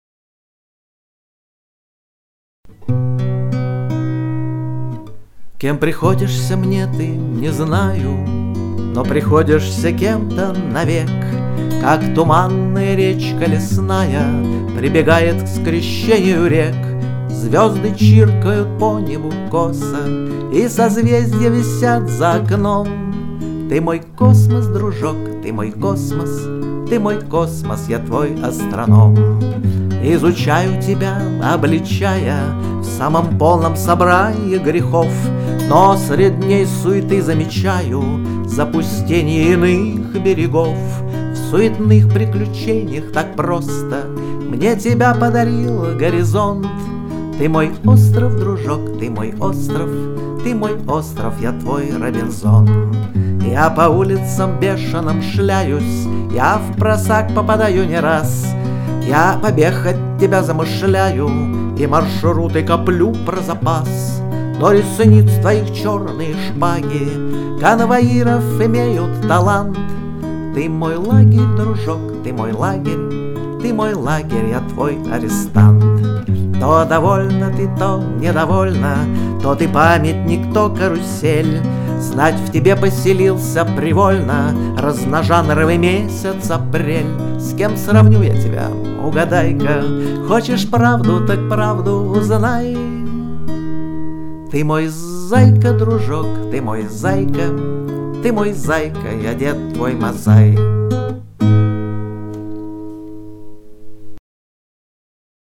Пение, гитара